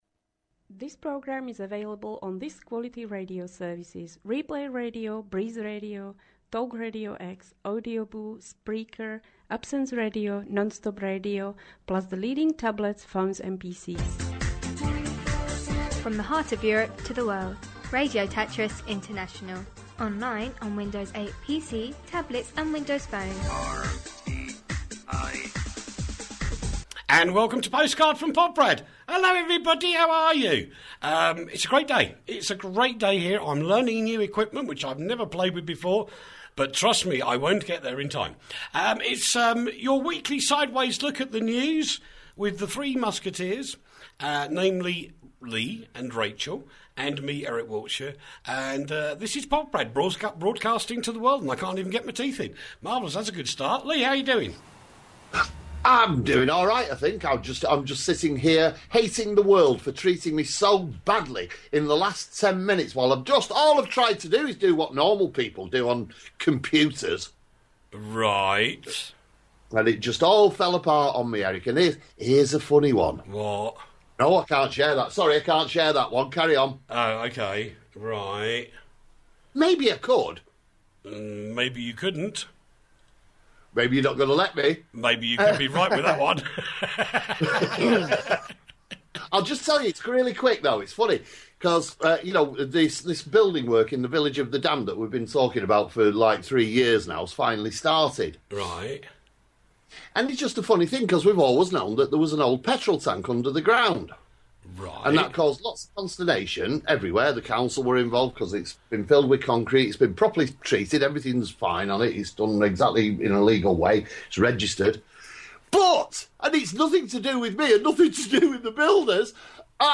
From The Heart Of Europe To The World, Radio Tatras International, Online, On Windows 8 PC, Tablet and Windows Phone. Postcard From Poprad the alternative news show